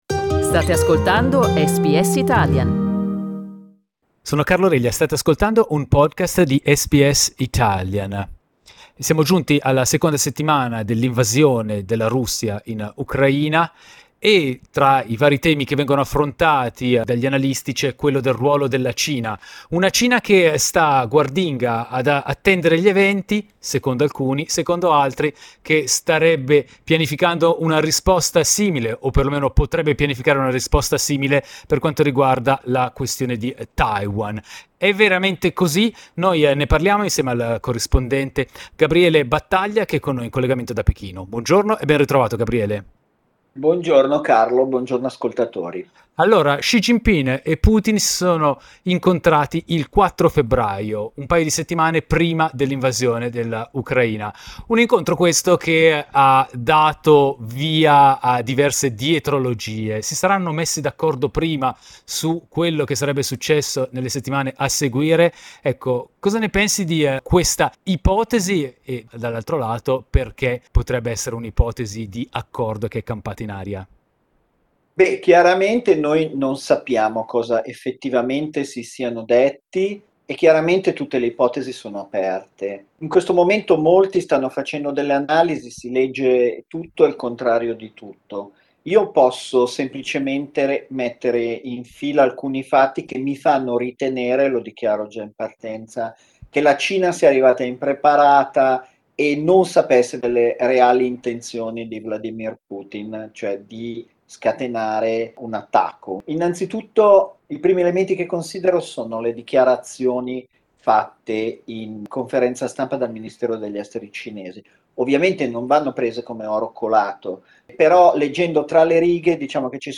Ascoltiamo perché nell'intervista a SBS Italian.